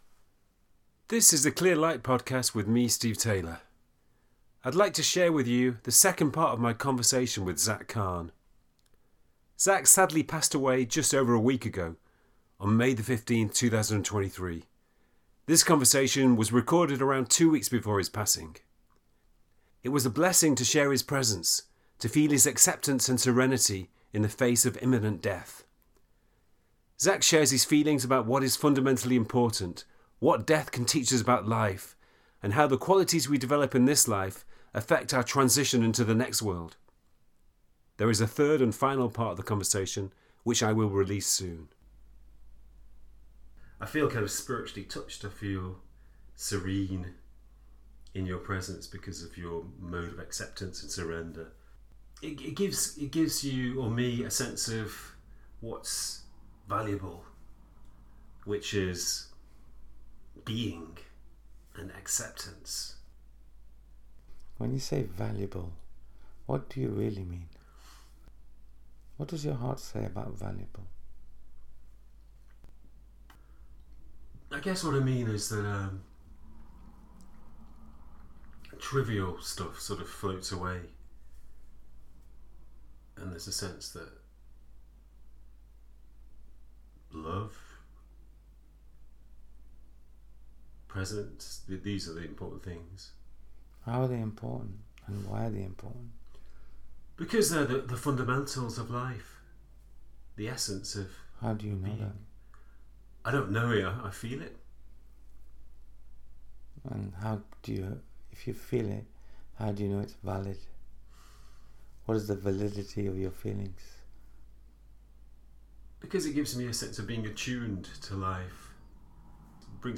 Travelling Lightly, Ascending Higher - A Conversation